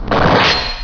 Sephiroth's sword, masamune, slashing
masaslsh.wav